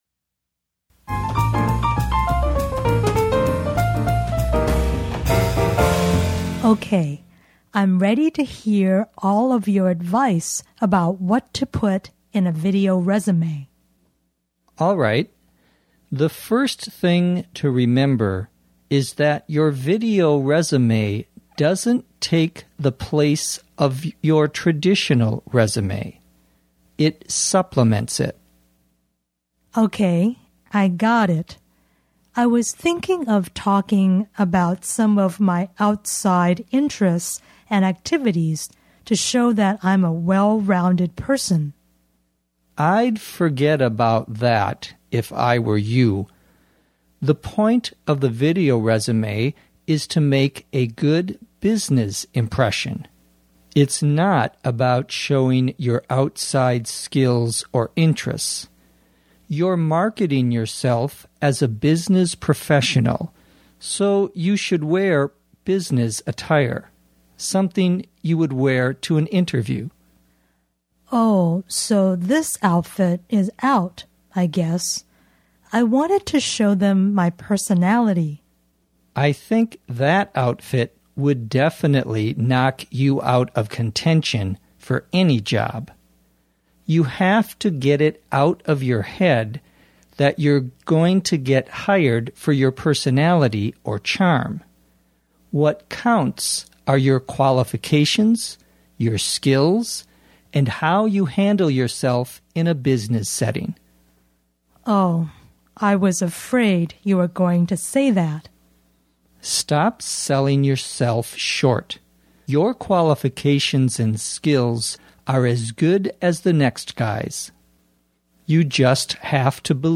地道美语听力练习:视频简历